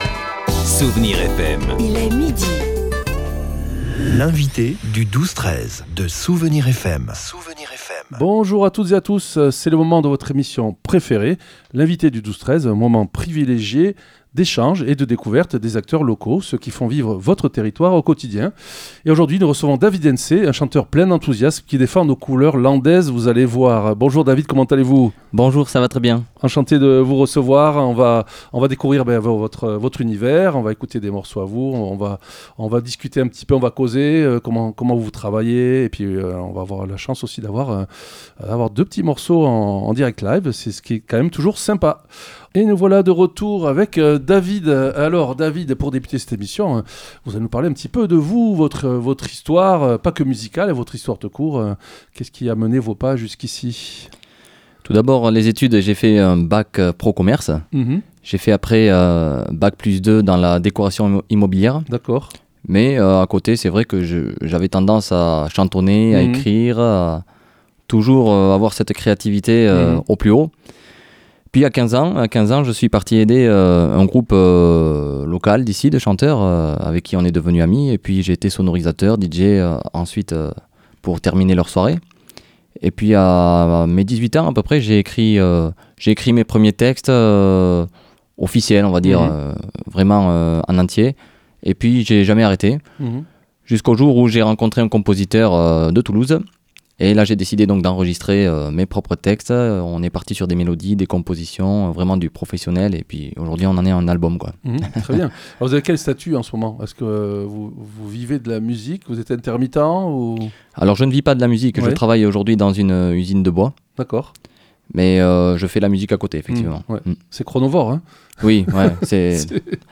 On a causé clip aussi, des projets et des fêtes landaises. Et on a écouté deux morceaux chantés en direct dans notre studio !